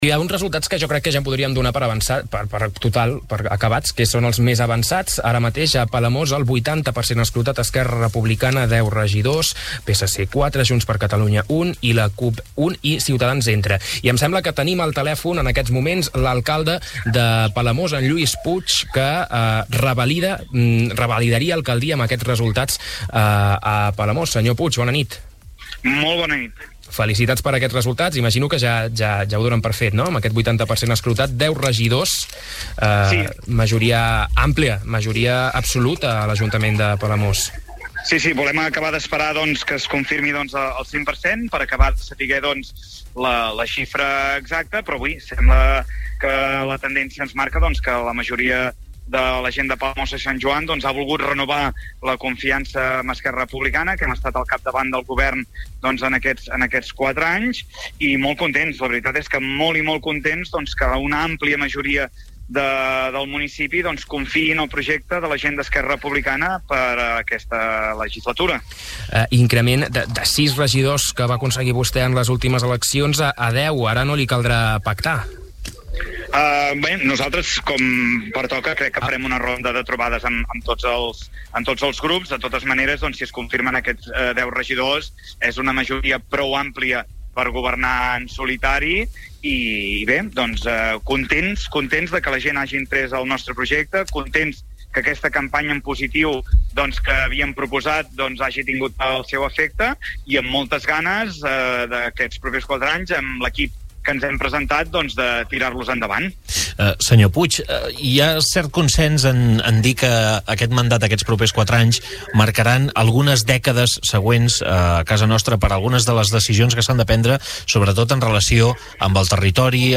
Lluís Puig ha passat aquest diumenge pels micròfons de Ràdio Capital i ha explicat que obrirà “una ronda de contactes amb tots els partits” però és conscient que té una majoria prou àmplia per governar en solitari.